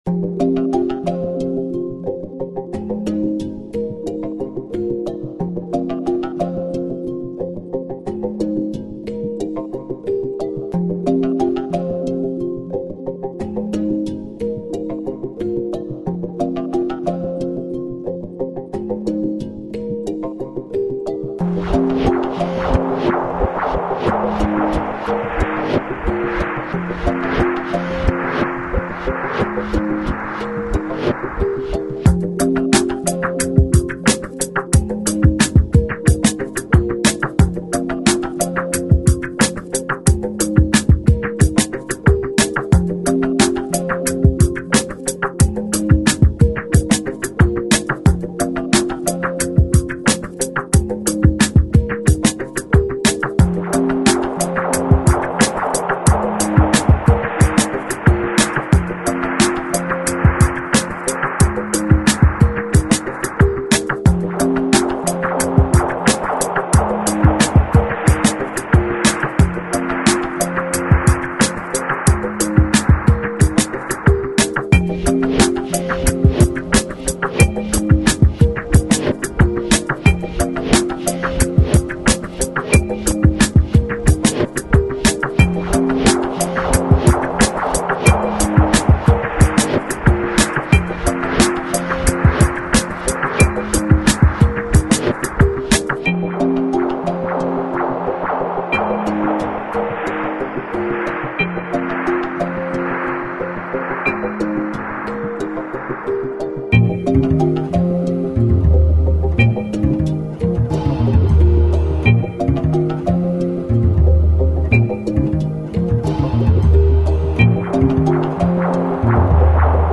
brano trip hop